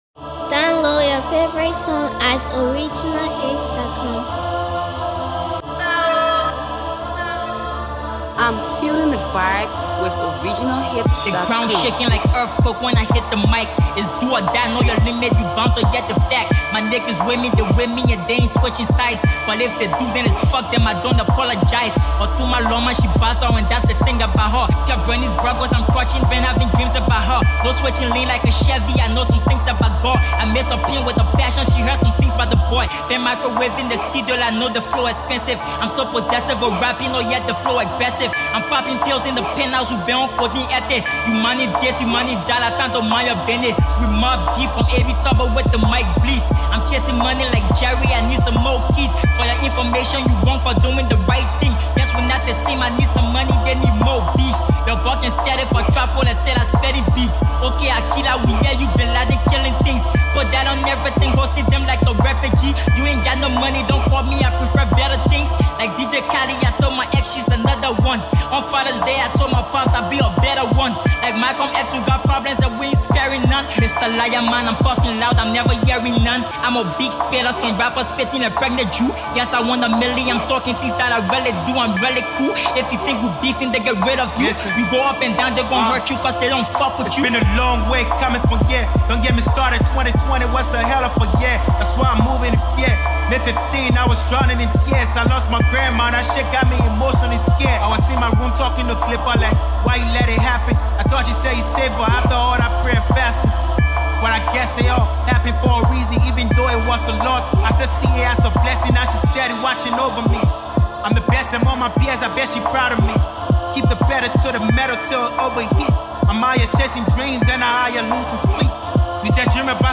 AfroAfro PopCypherFeaturedHip hop musicHipcoMusicTrapco